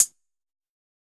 UHH_ElectroHatD_Hit-01.wav